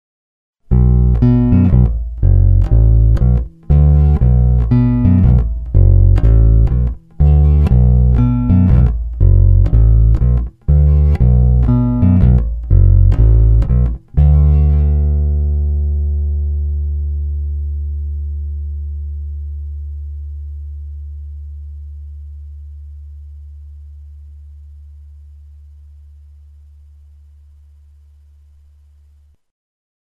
Lakland 55-02 Deluxe, struny Sandberg nehlazená ocel asi měsíc staré, aktivka zapnutá, korekce na střed, hráno prsty.
Krkový singl, aktivka na rovinu